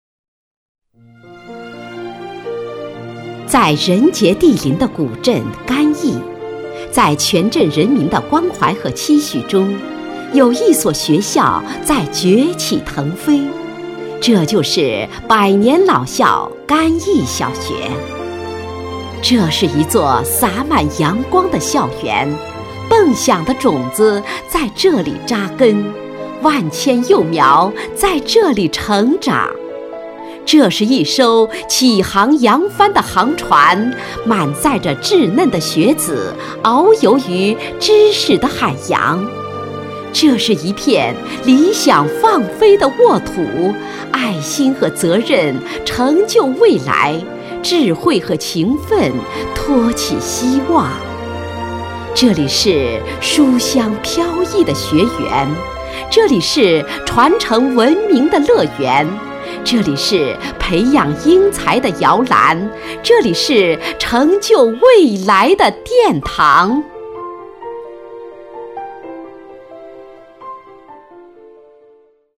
女国87_专题_学校_小学_抒情.mp3